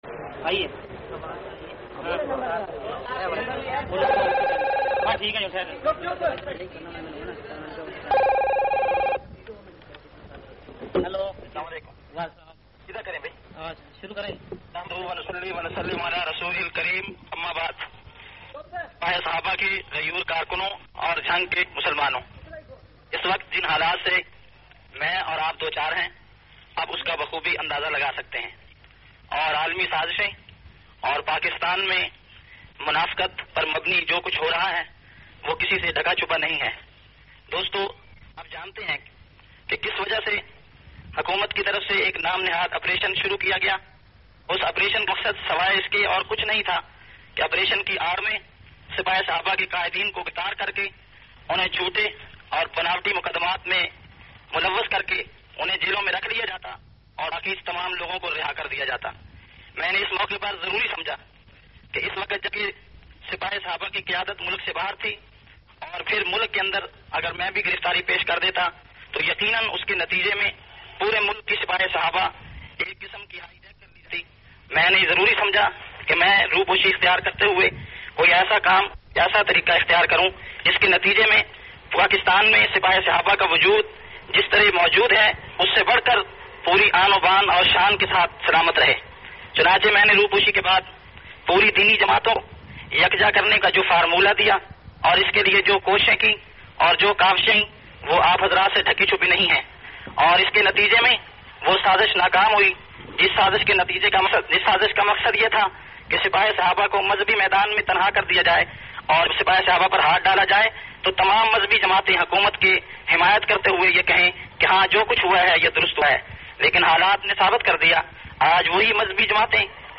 61- Karkunon se telephonic khittab Jhang.mp3